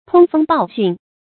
通風報訊 注音： ㄊㄨㄙ ㄈㄥ ㄅㄠˋ ㄒㄩㄣˋ 讀音讀法： 意思解釋： 見「通風報信」。